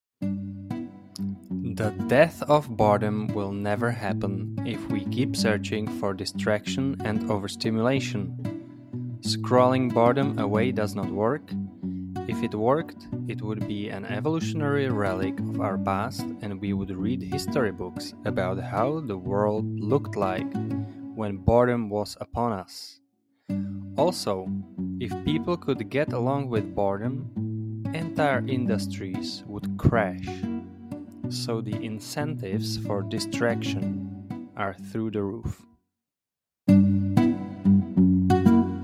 Svůj klidný hlas mohu nahrát na profesionální mikrofon a poslat Vám text, který si zvolíte, v audioformátu, který si vyberete.
Klidný mužský hlas